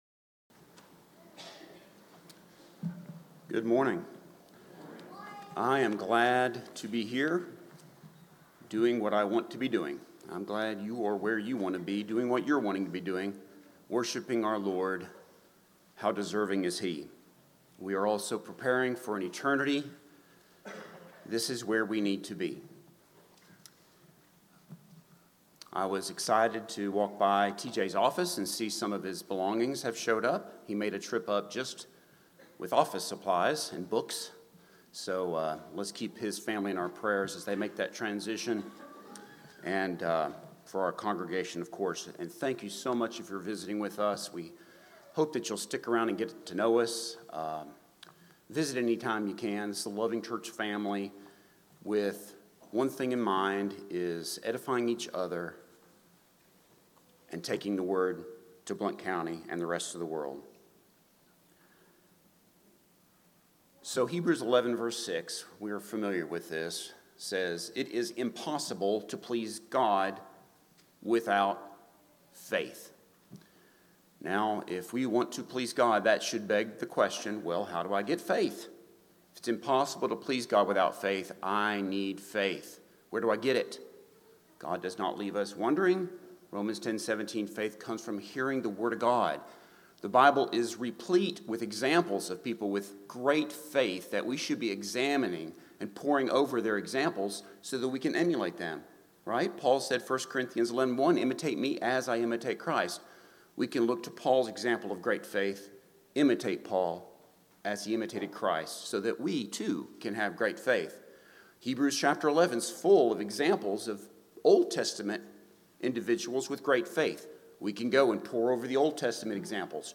Eastside Sermons Passage: 1 Peter 1:6-7 Service Type: Sunday Morning « Defending the Truth